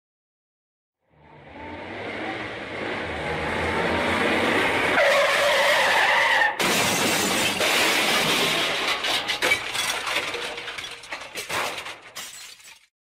Car Crashs Sound
transport
Car Crashs